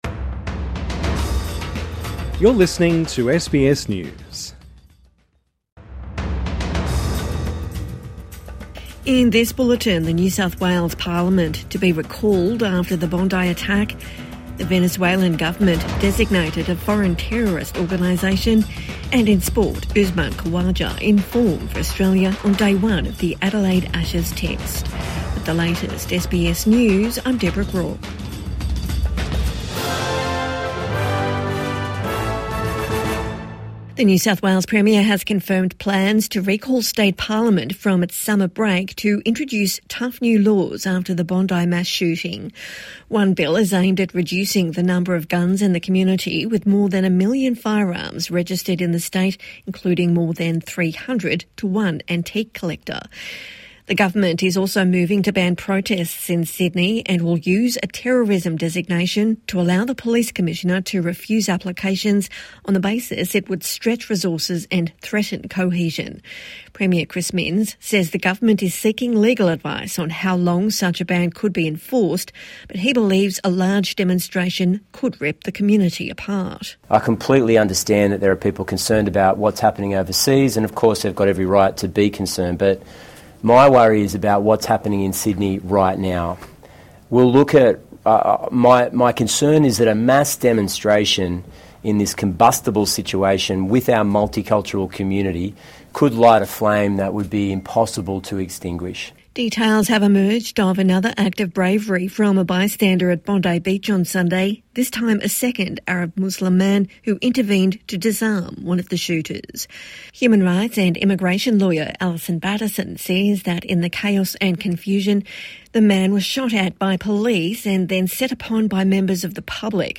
NSW parliament to be recalled over gun, protest laws | Evening News Bulletin 17 December 2025